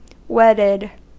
Ääntäminen
Synonyymit married Ääntäminen US Tuntematon aksentti: IPA : /ˈwɛdəd/ Haettu sana löytyi näillä lähdekielillä: englanti Käännöksiä ei löytynyt valitulle kohdekielelle.